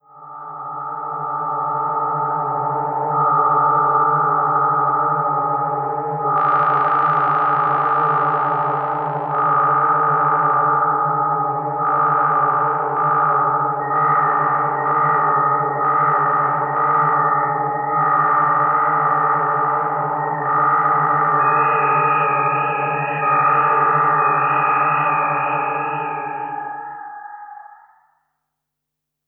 FLAME "FM KOSMOS" Quad polyphonic FM synthesizer
6 - Lofi Drone
6_LofiDrone.wav